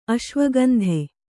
♪ aśvagandhe